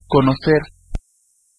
Ääntäminen
IPA : /miːt/